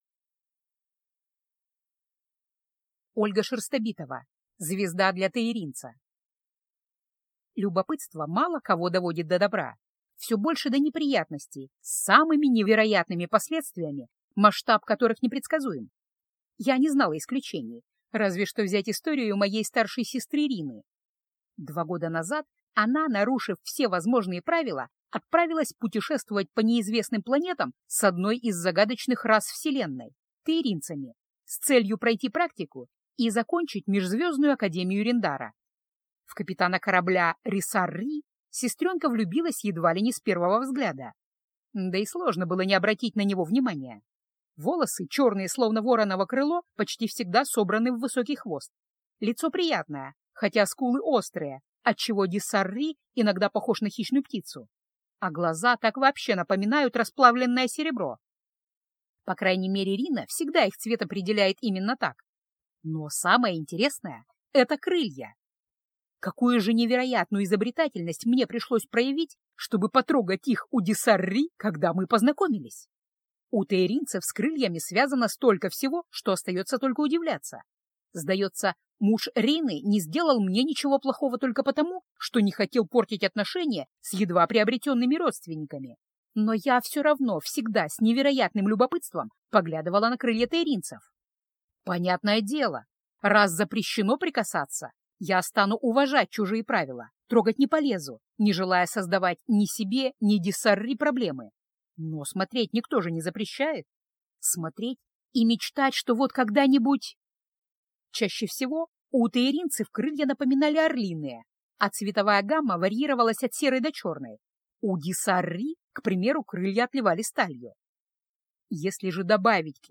Аудиокнига Звезда для тейринца | Библиотека аудиокниг